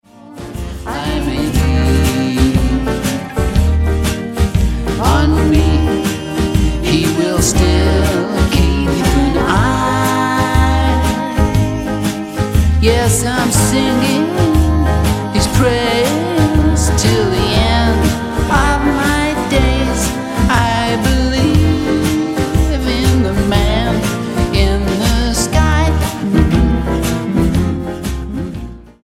STYLE: Rock
wispy vocals are bolstered by layered background vocals